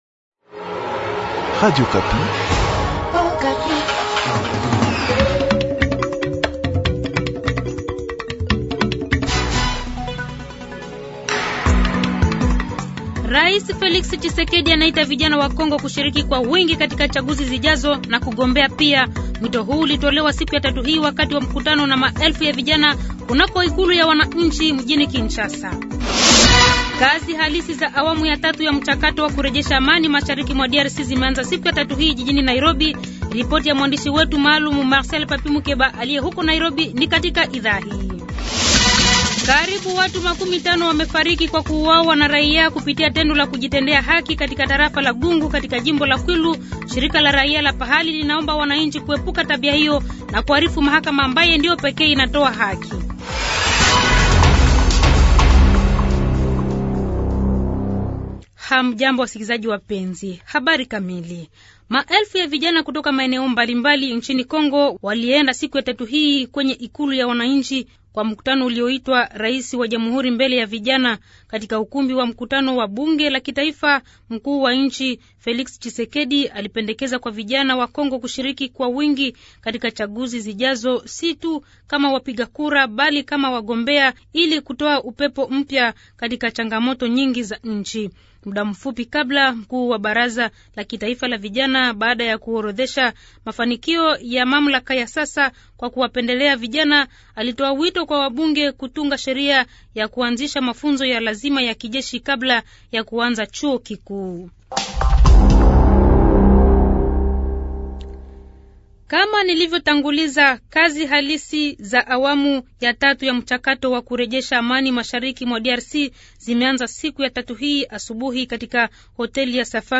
Journal Soir
Habari za siku ya tatu jioni tarehe 30/11/2022